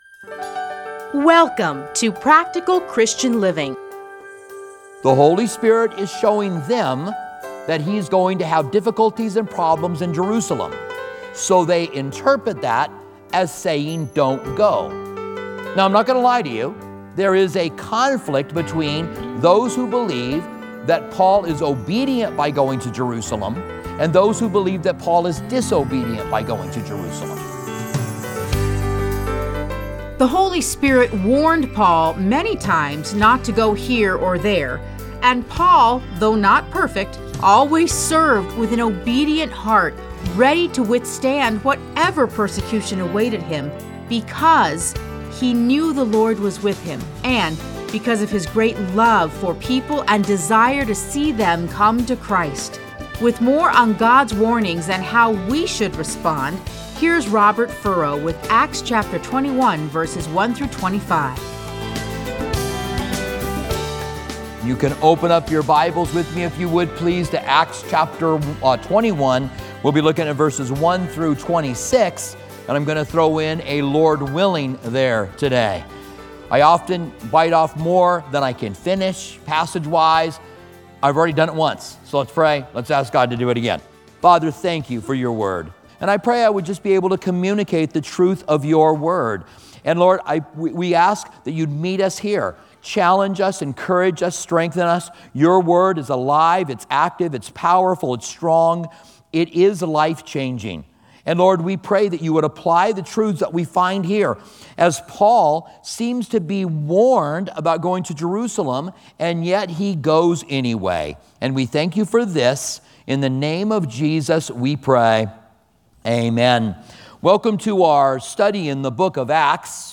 Listen to a teaching from Acts 21:1-25.